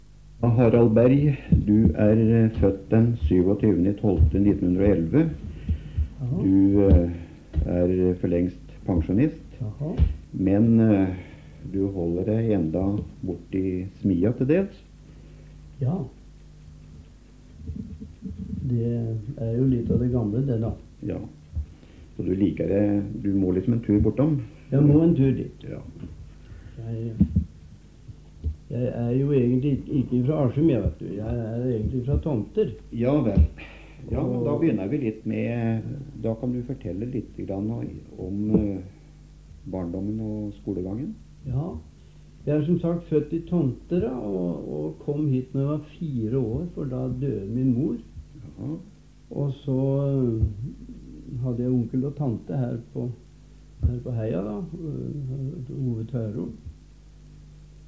Det er i all hovedsak intervjuer med gamle eller sentrale folk i Askimsamfunnet.
Noen er om folks oppvekst, men det kan også være om spesielle hendelser. Dessverre er noen opptak i dårlig kvalitet. De to viktigste kategoriene er fra CD-plater med intervjuer og gamle spolebånd der noen opptak er fra før 1950.